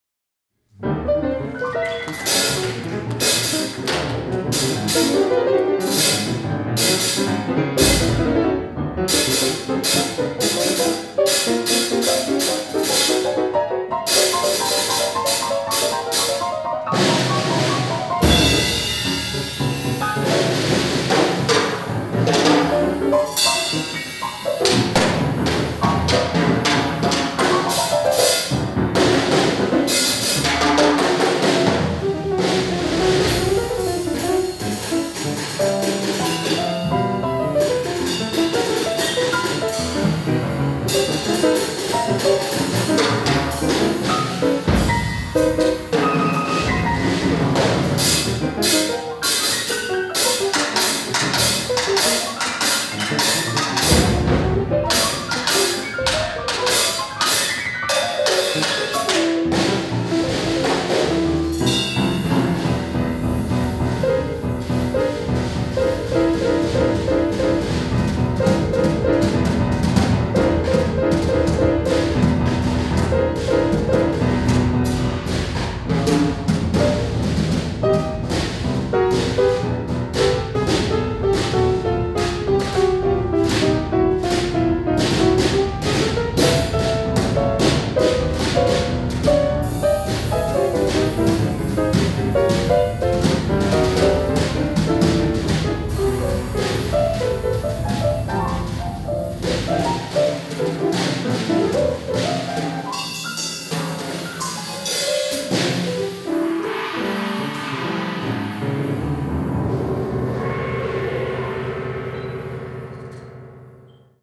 free jazz and improvised music
piano
drums